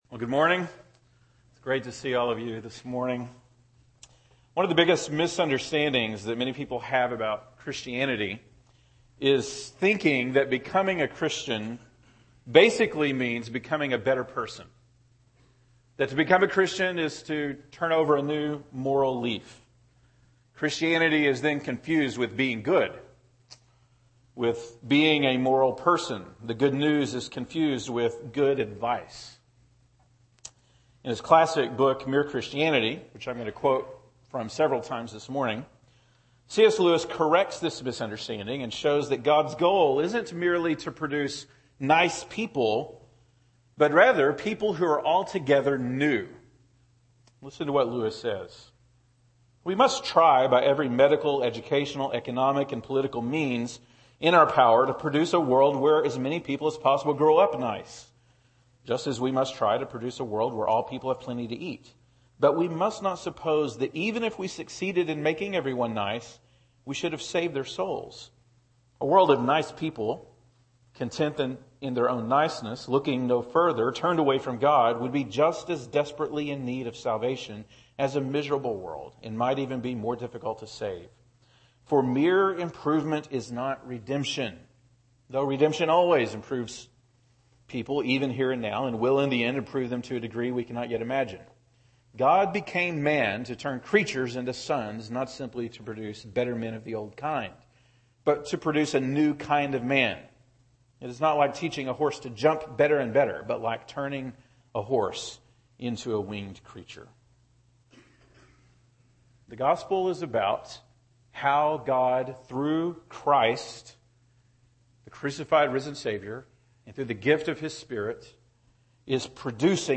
August 31, 2014 (Sunday Morning)